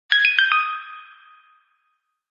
Стандартная мелодия на Google Nexus 4